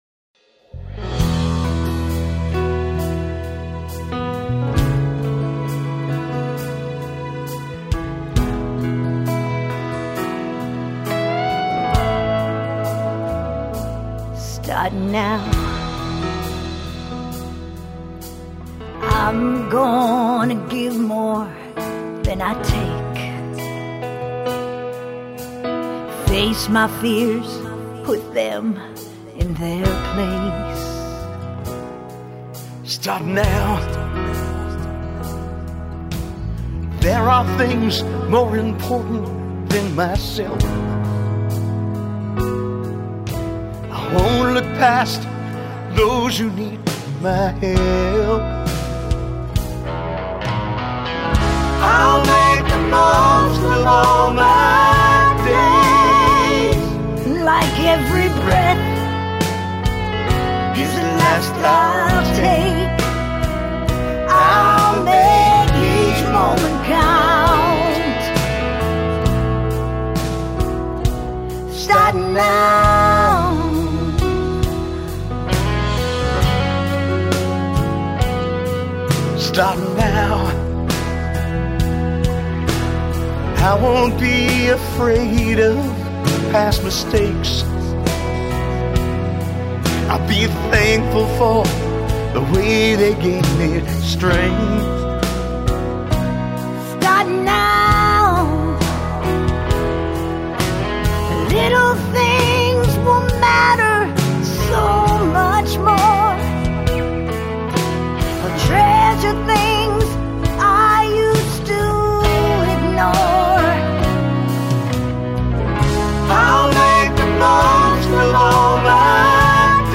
**GENRE: COUNTRY.